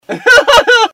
Laugh 30